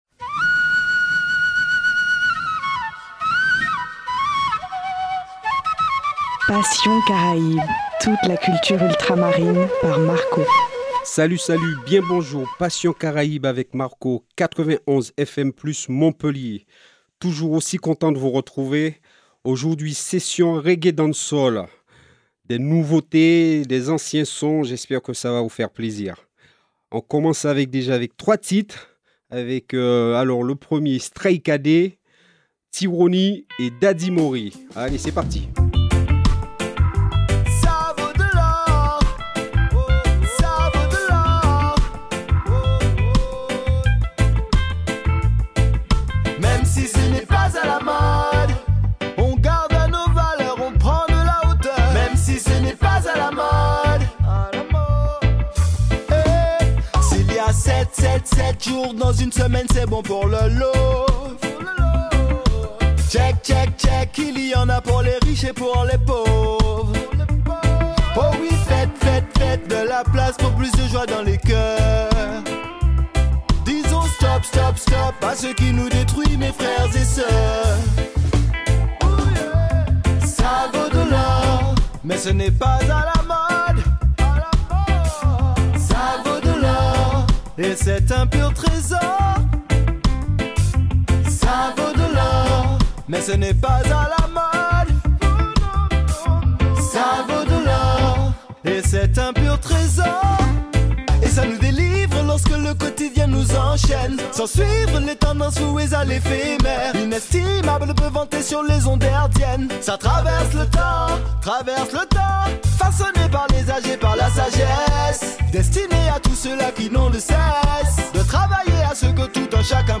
Passion « Caraïbes » #14 : reggae dance hall